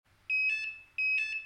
• Signalton bei niedrigem Batteriestand ~4 h vor Abschaltung
Niedriger Batteriestand
chouka_son_low_bat.mp3